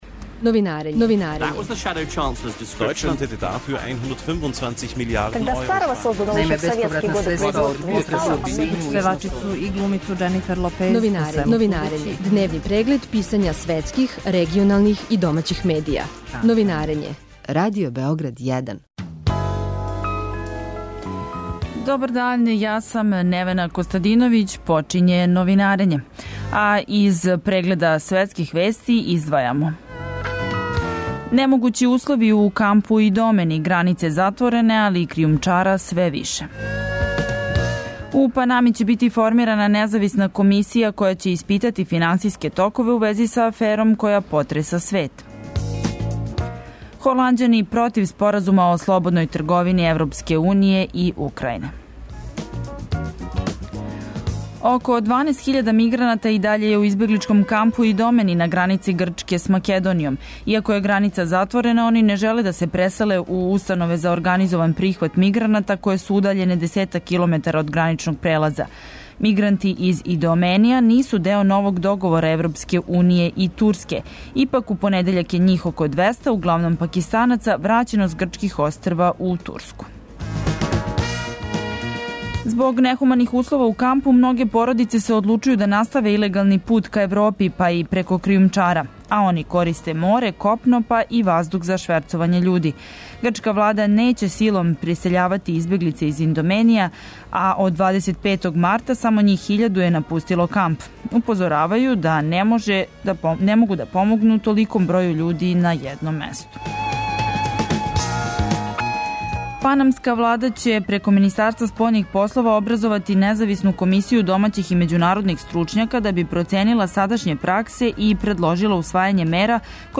Гост Новинарења је бивши амбасадор Србије у Либији Душан Симеоновић.